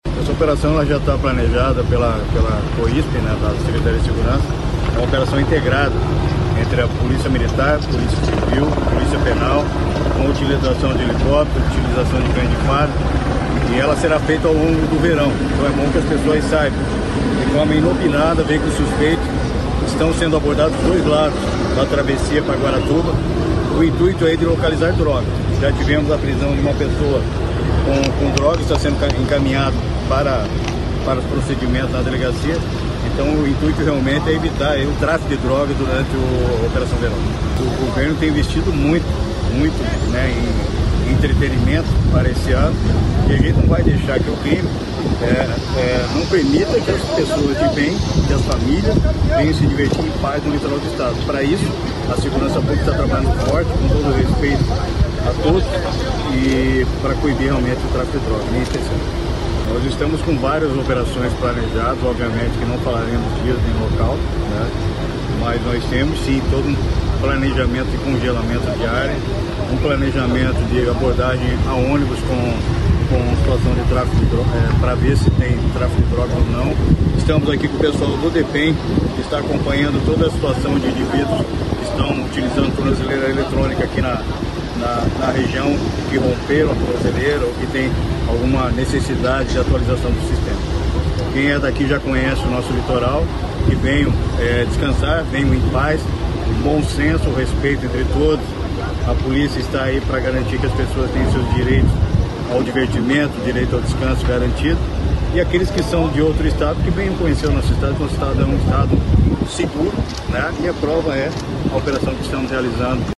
Sonora do secretário da Segurança Pública, Hudson Teixeira, sobre a primeira grande ação das forças policiais no Verão Maior Paraná